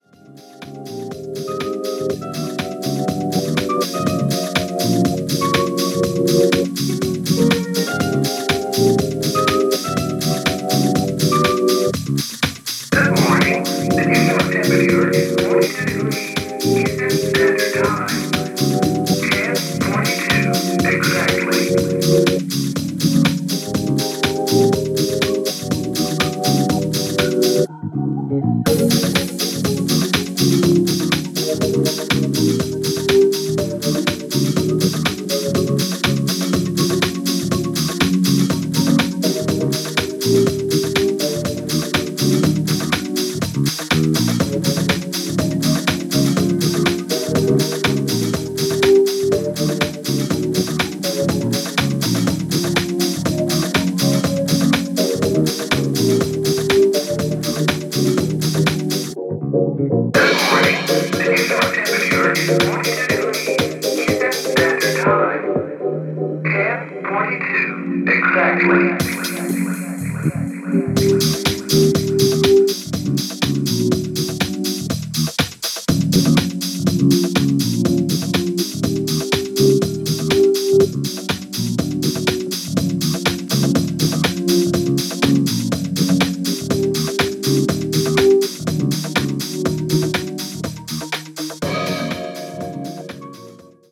Dead Stock（試聴録りしました）